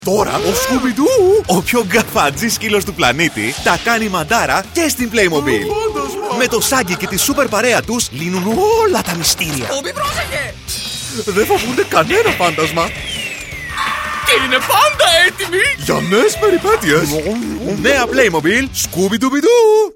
Impersonations
BassDeepLow
WarmCharming